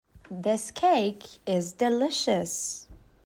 جدول کلمات، جمله ها و معنی آن به همراه تلفظ با سه سرعت مختلف:
تلفظ با سرعت‌های مختلف